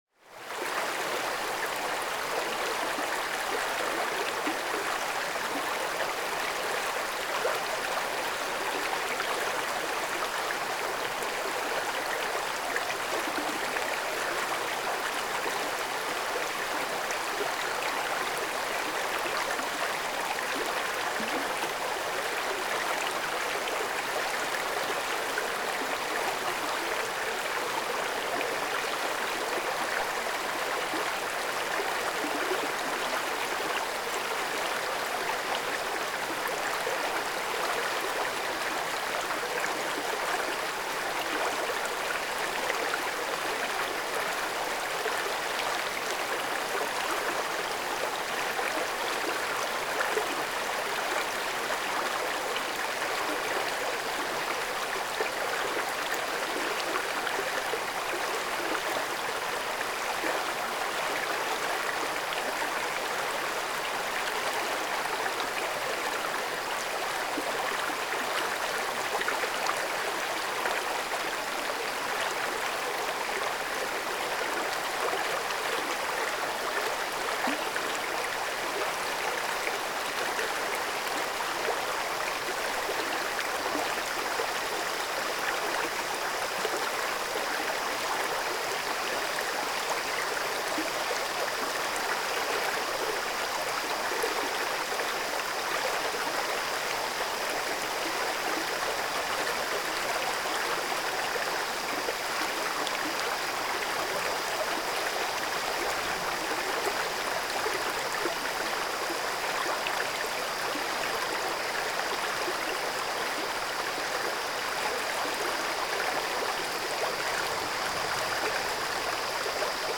小川のせせらぎ03 - 音アリー
river_stream_03.mp3